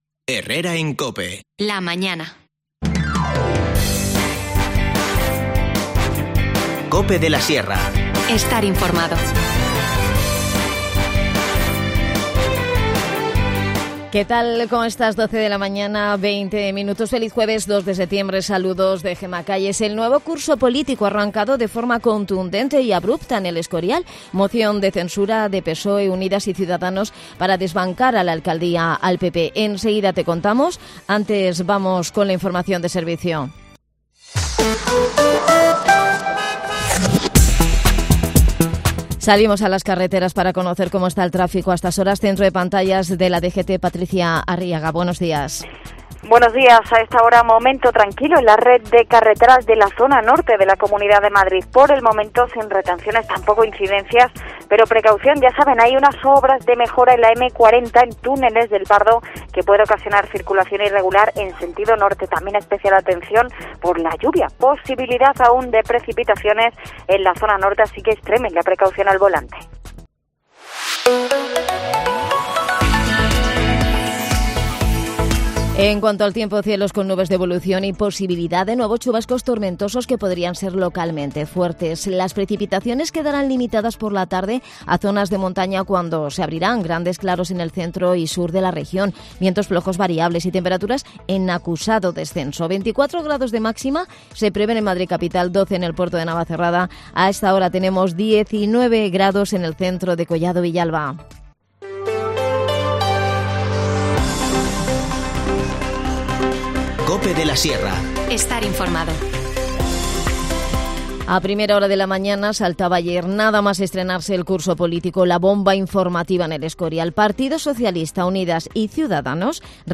Nos cuenta todos los detalles Miguel Partida, concejal de Deportes.